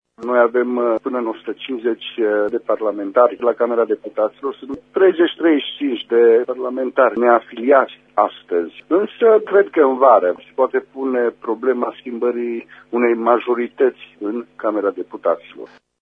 În ceea ce priveşte schimbarea majorităţii parlamentare dorită de liberali, deputatul PNL de Mureș, Cristian Chirteș, crede că acest lucru este foarte posibil la Senat, dar în camera Deputaţilor se va întâmpla abia în vară.